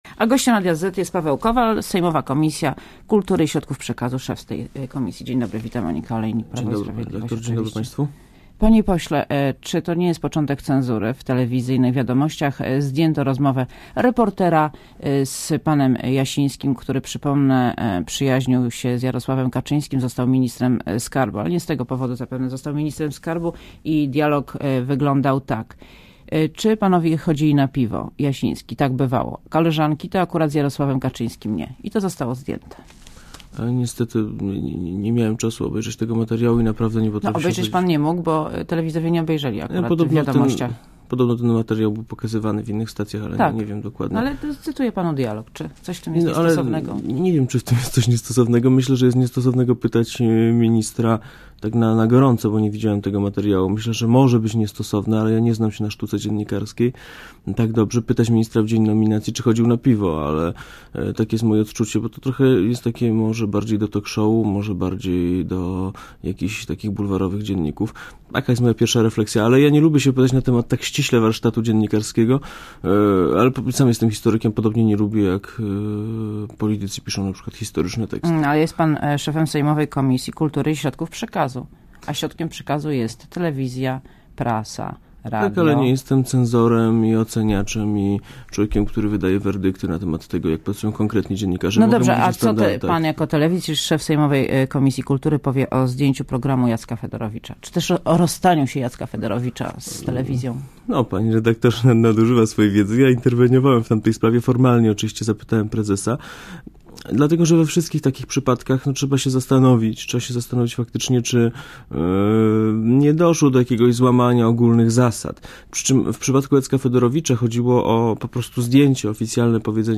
Gościem Radia ZET jest Paweł Kowal, szef sejmowej komisji kultury środków przekazu, Prawo i Sprawiedliwość. Wita Monika Olejnik, dzień dobry.